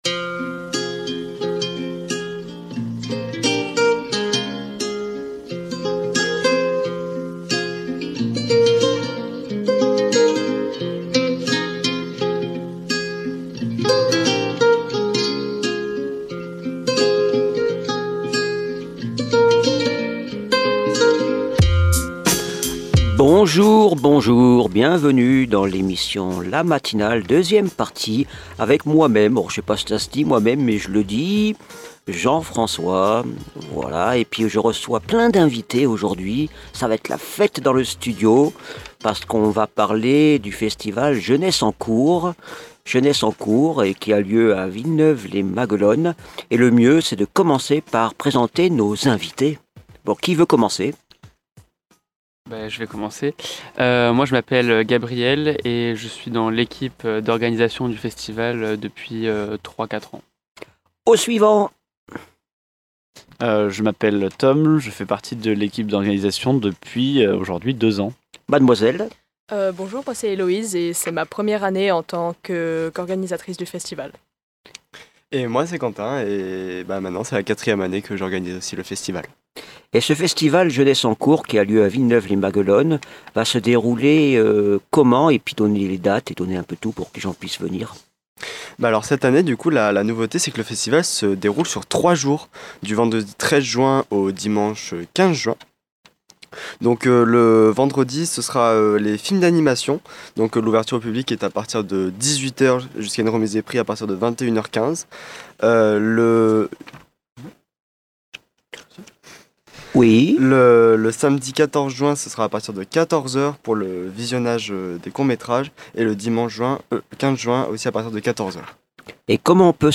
Dans le grand entretien de la matinale du 21 mai